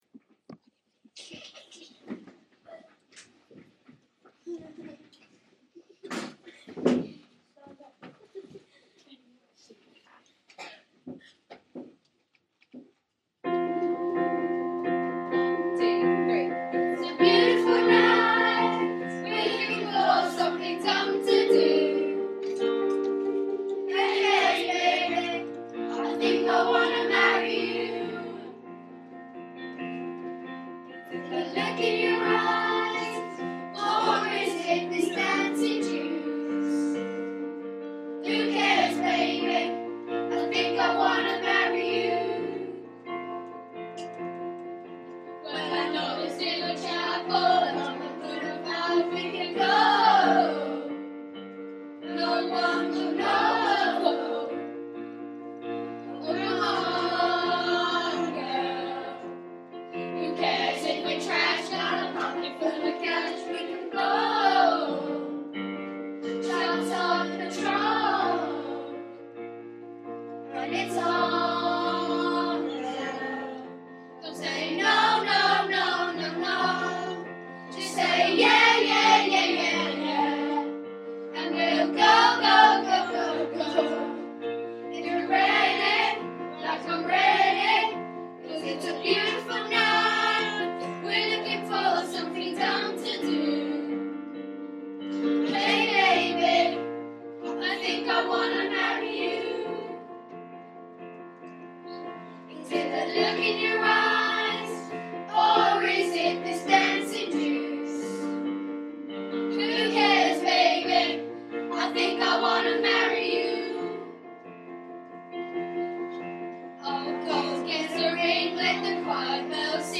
7Jam 1st rehearsal